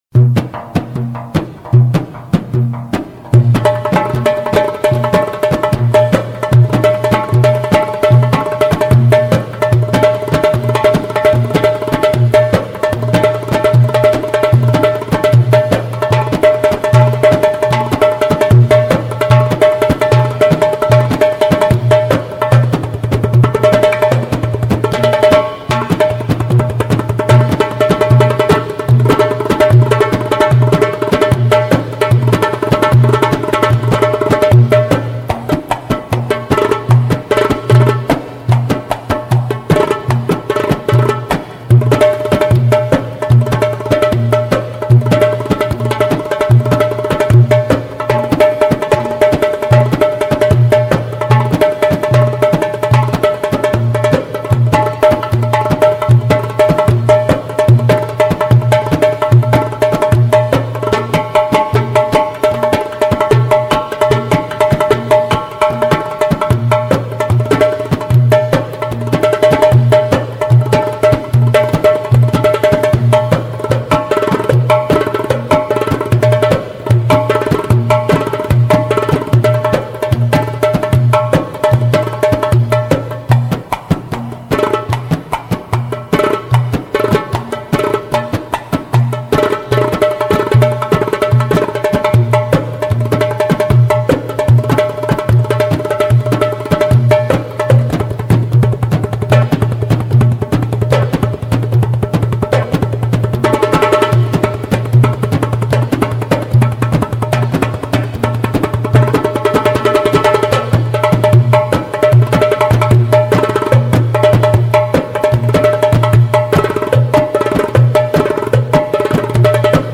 Барабаны (инструментальная)